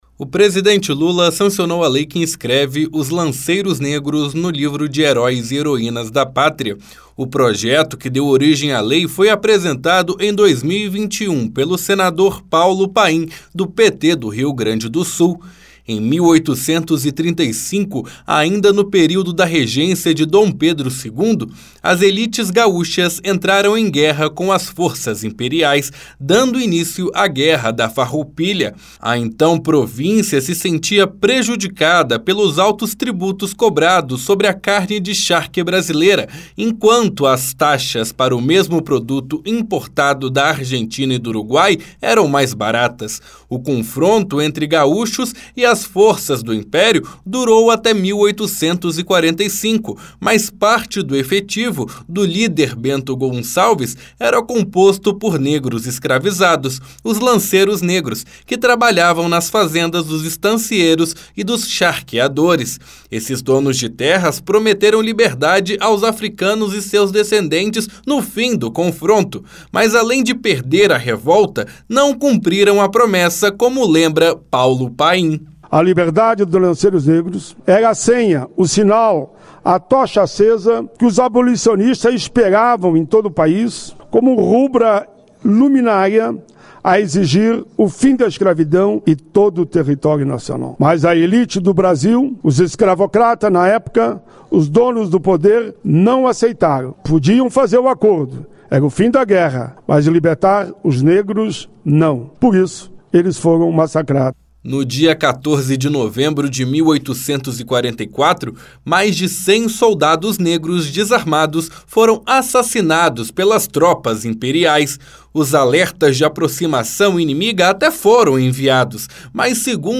A secretária de Cultura do Rio Grande do Sul, Beatriz Araujo, comenta que a nova lei ajuda a contar a história gaúcha.